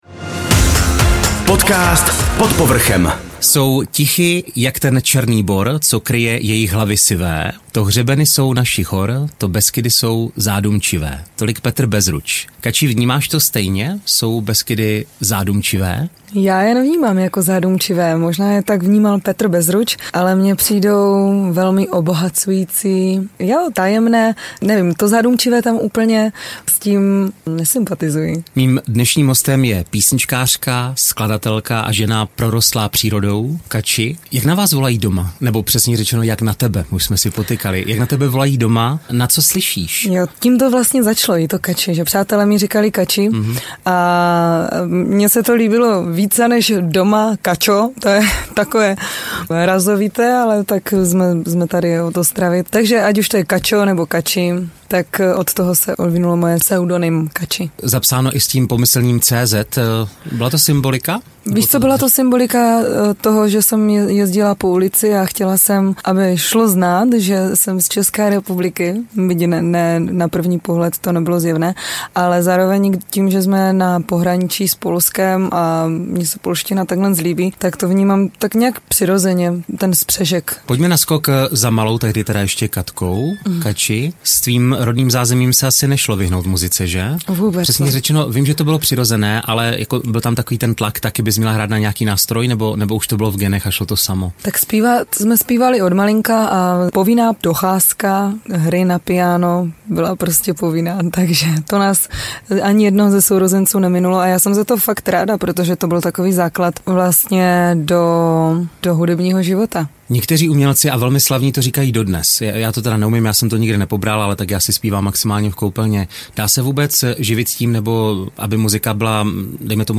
Těšte se na inspirativní příběhy, smích i nálož pozitivní energie